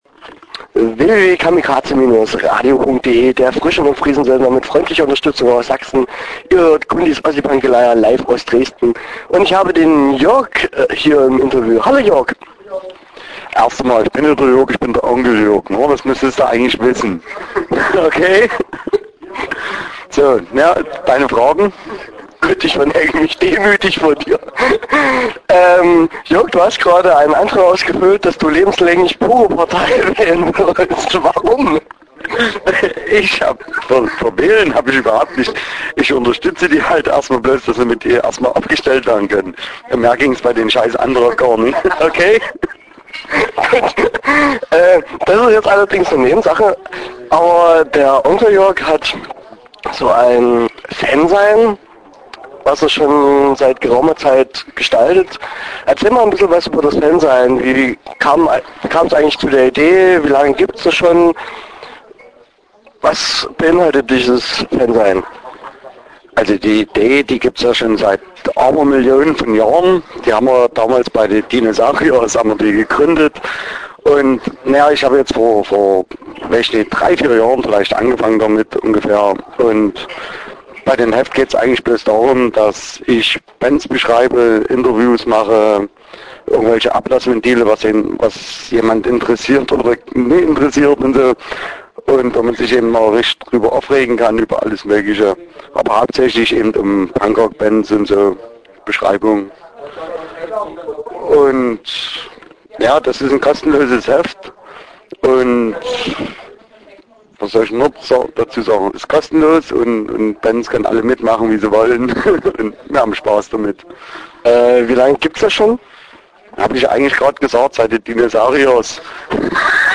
Start » Interviews » Sammlerschrank-Heft